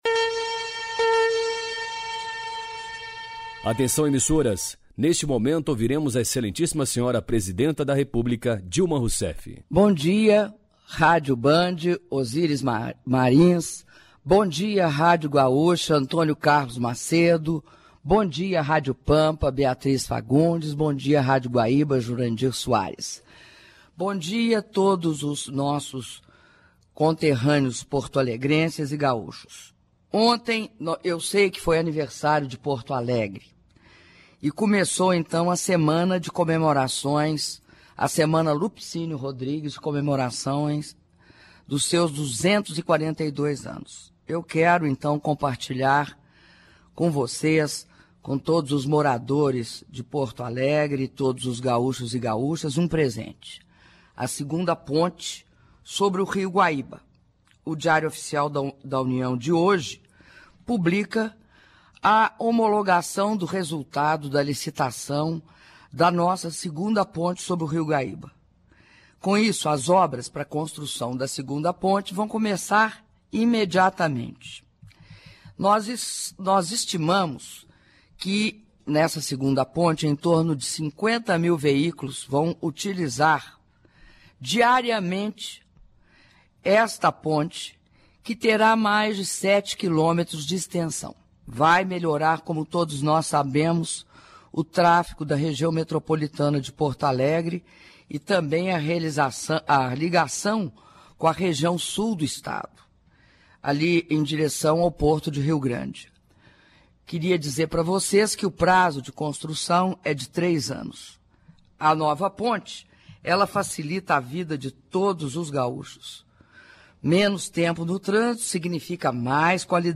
Áudio do anúncio da Presidenta da República, Dilma Rousseff, à rádios Gaúchas sobre o início das obras de construção da nova ponte de travessia do Rio Guaíba (03min42s)